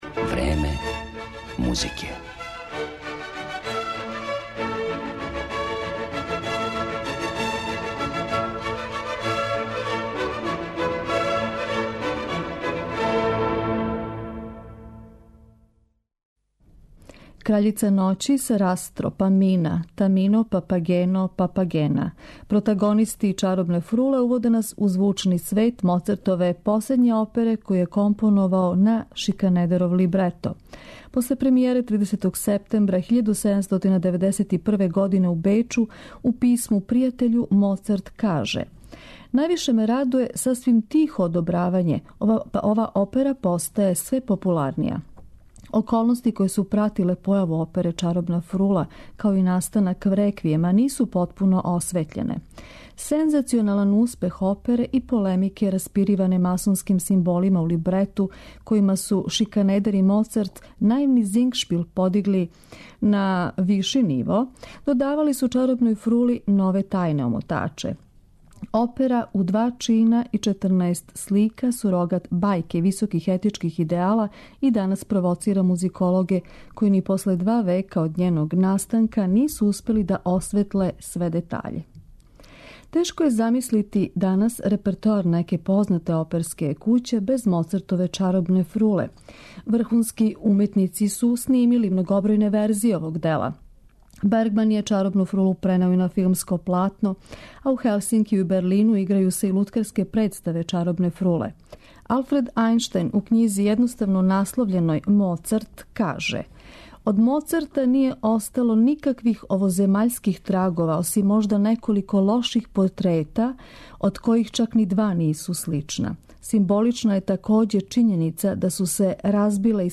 Околности које су пратиле настанак "Чаробне фруле" нису потпуно осветљене, а масонски симболи у либрету помогли су Моцарту да обликује ово музичко-сценско дело у два чина и 14 слика као сурогат бајке и високих етичких идеала.